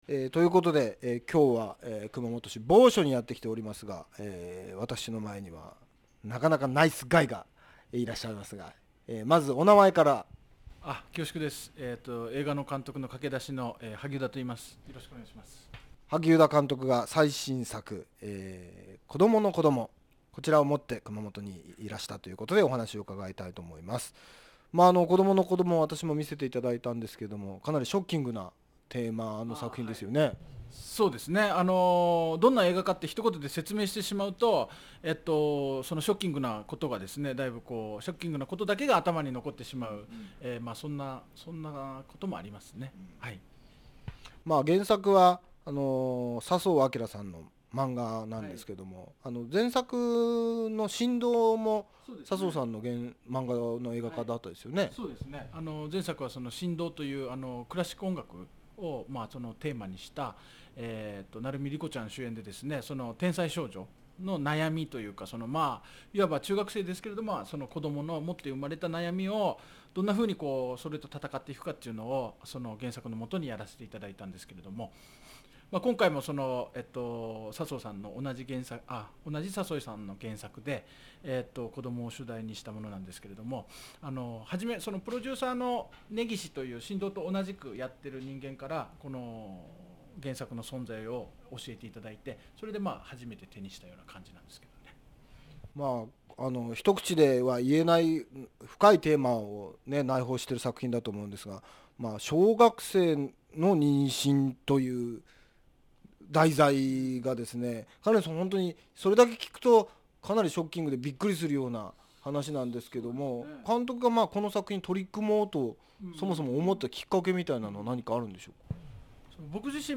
FMラジオ局・エフエム熊本のポッドキャスト。
ついにクランクアップした行定勲監督の最新作「今度は愛妻家」。 打ち上げで起きたある出来事について、監督自ら語っています。